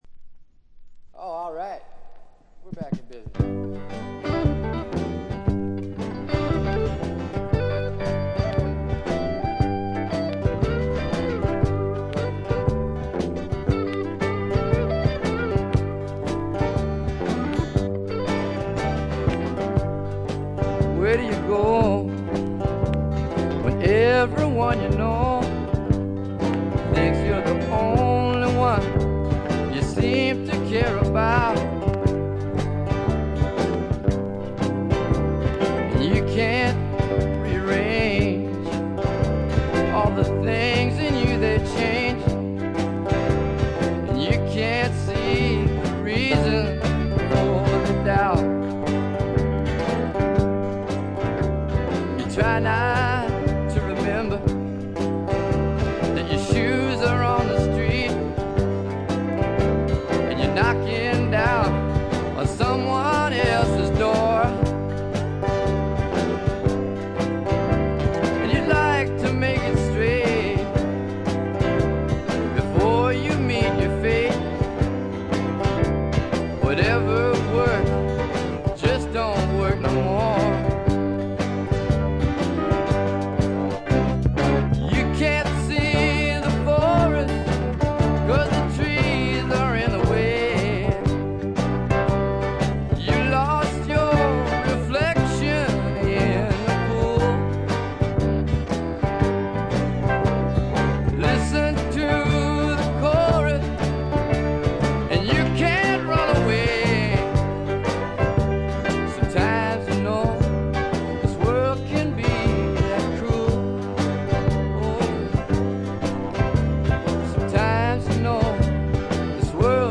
B1冒頭で軽い周回ノイズ。
中身はヴォーカルも演奏も生々しくラフなサウンドがみっちり詰まっている充実作で、名盤と呼んでよいでしょう。
試聴曲は現品からの取り込み音源です。